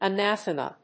a・nath・ema /ənˈæθəmə/
• / ənˈæθəmə(米国英語)